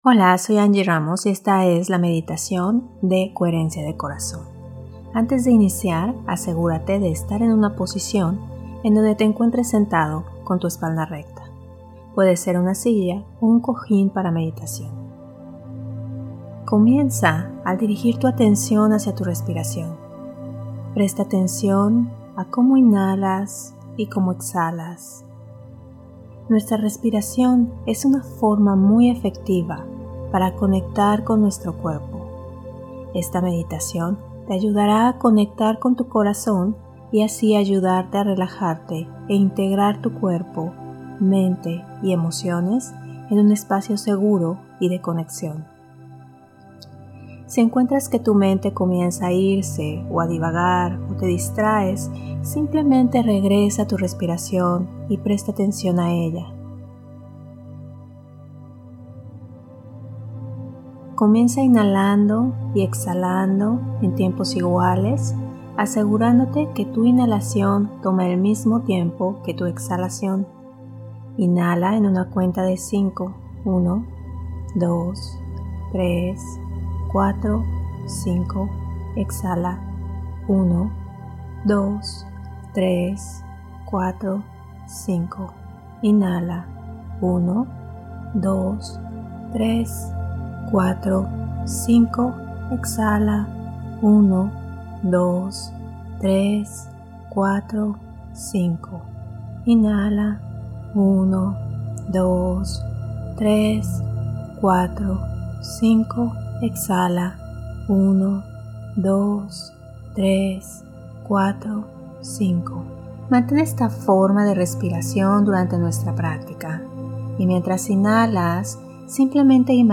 Meditación Coherencia de Corazón
Meditacion heart math - coherencia de corazon.mp3